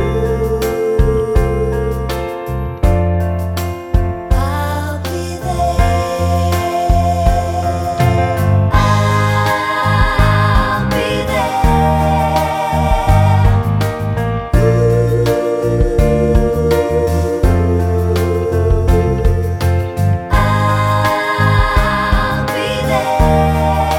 no Backing Vocals Duets 4:15 Buy £1.50